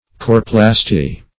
Meaning of coreplasty. coreplasty synonyms, pronunciation, spelling and more from Free Dictionary.
Search Result for " coreplasty" : The Collaborative International Dictionary of English v.0.48: Coreplasty \Cor"e*plas`ty\ (k[o^]r"[-e]*pl[a^]s`t[y^]), n. [Gr. ko`rh pupil + -plasty.]